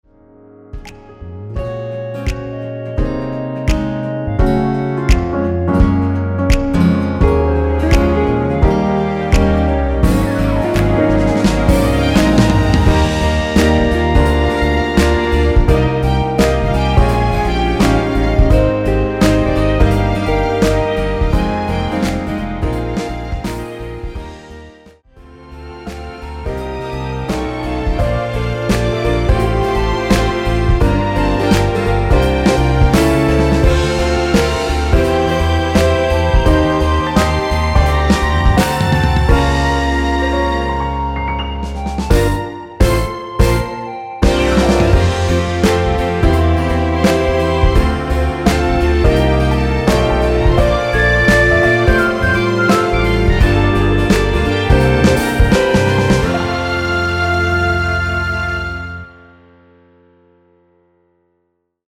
엔딩이 페이드 아웃이라 노래 하기 좋게 엔딩을 만들어 놓았습니다.(미리듣기 참조)
원키(짧은편곡) 멜로디 포함된 MR입니다.
Db
앞부분30초, 뒷부분30초씩 편집해서 올려 드리고 있습니다.
(멜로디 MR)은 가이드 멜로디가 포함된 MR 입니다.